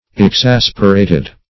Exasperate \Ex*as"per*ate\, v. t. [imp.